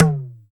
LOGTOM HI F.wav